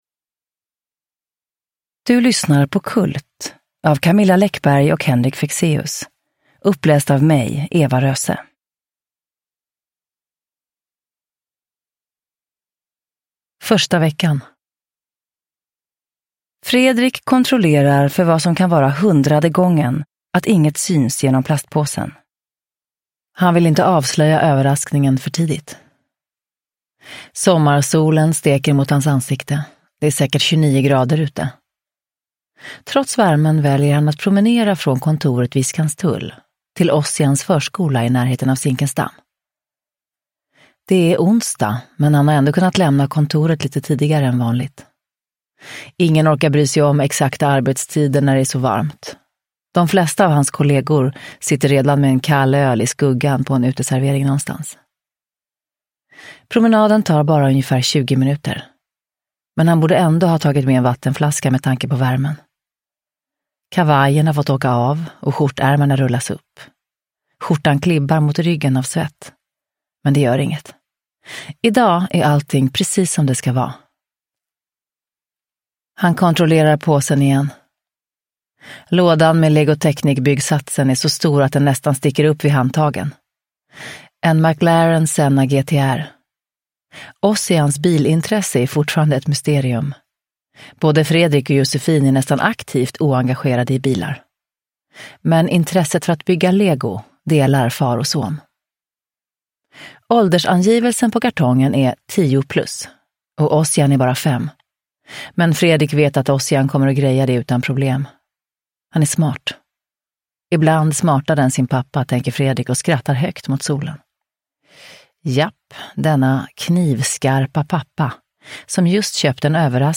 Uppläsare: Eva Röse
Ljudbok